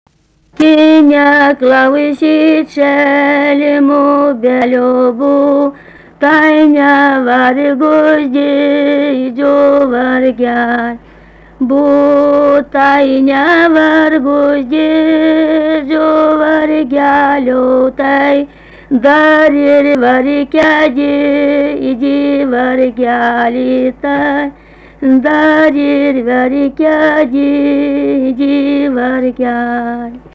Subject daina
Erdvinė aprėptis Kriokšlys
Atlikimo pubūdis vokalinis
Pastabos 3 balsai